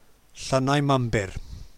To hear how to pronounce Llynnau Mymbyr, press play:
llynnau_mymbyr.mp3